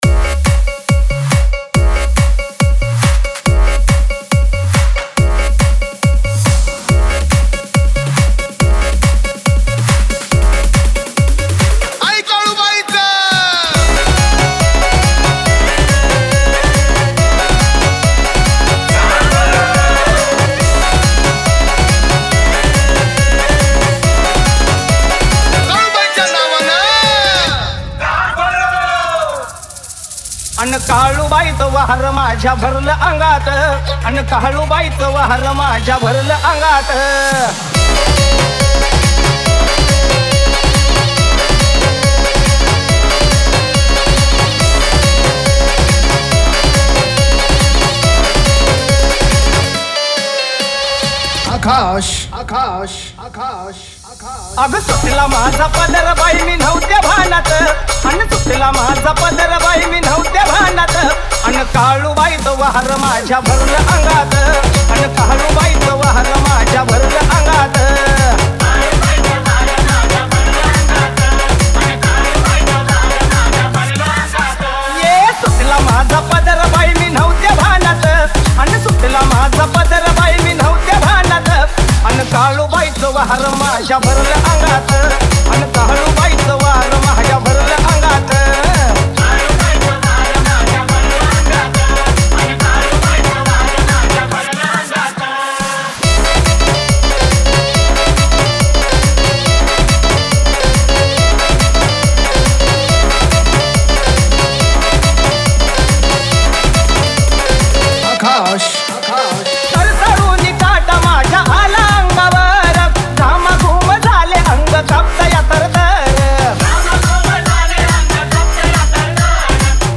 Navratri Dj Remix Song Play Pause Vol + Vol -